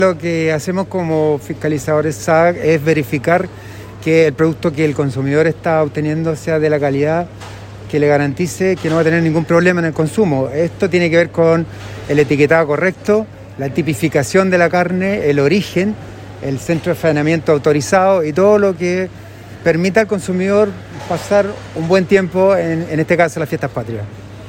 AUDIO :Director SAG Jorge Mautz